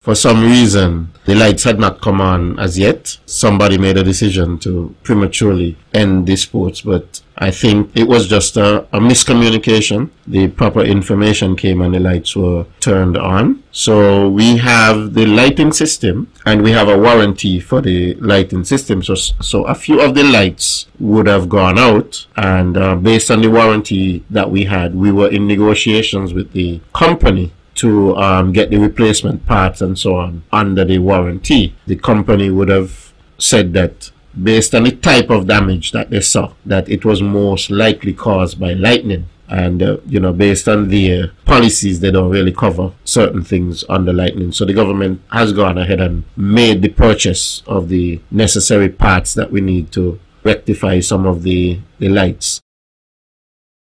Nevis’ Minister of Sports, Troy Liburd.